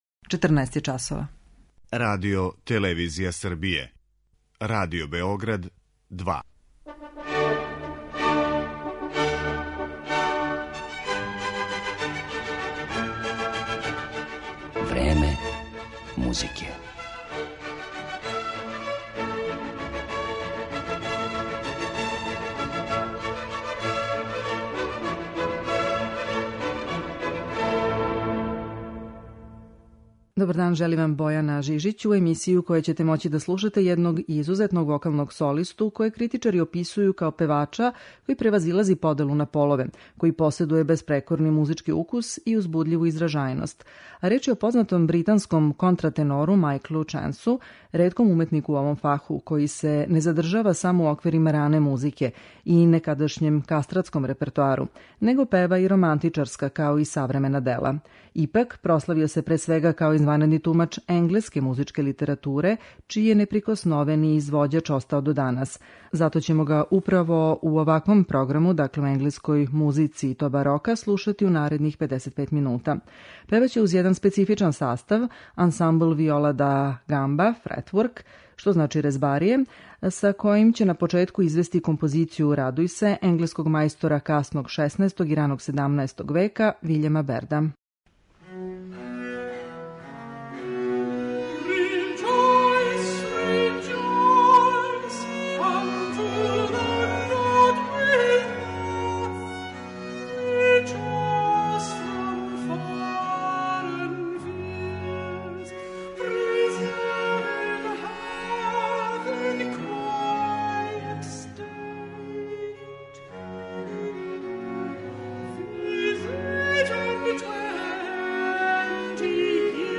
Слушаћете га како, са такође британским ансамблом, изванредним консортом виола Fretwork , изводи дела ренесансних и барокних композитора који су обележили музичку историју ове земље.